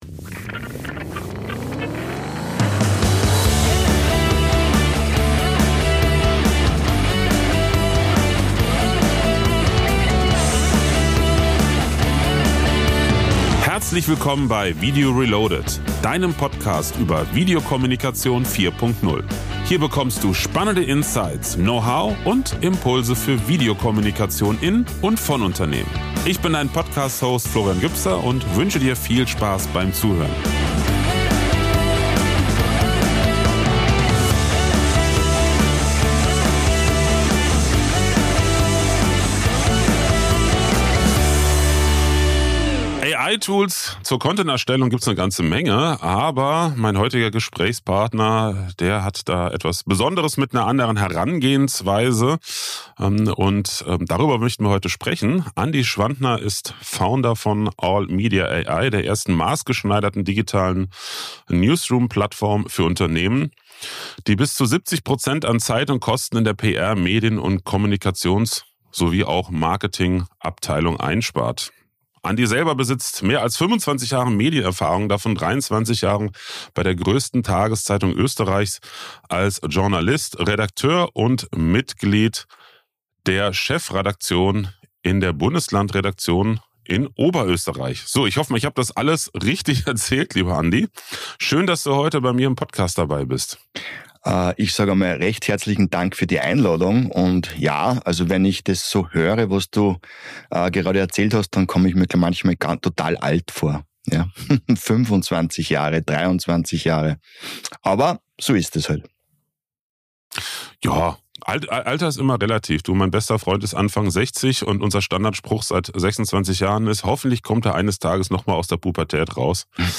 Ich freue mich, dass ich diesmal Gast in meinem eigenen Podcast bin!